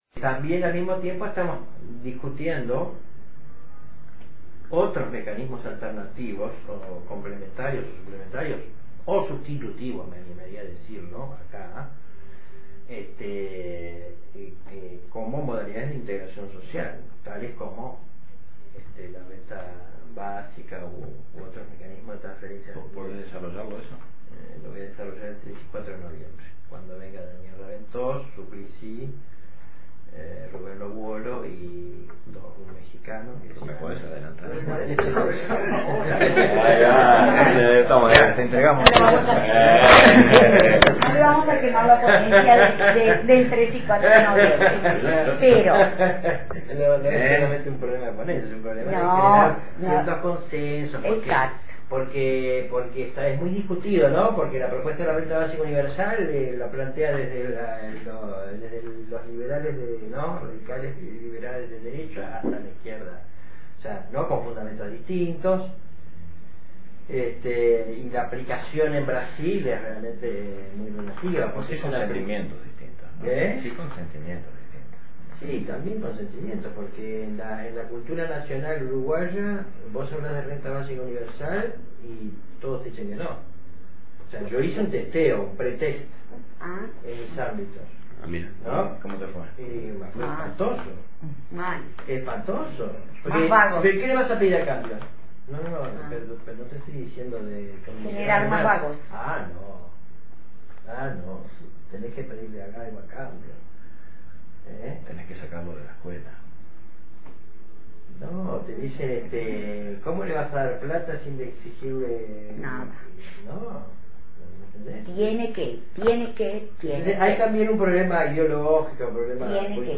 conversacion sobre la renta basica(editado).wma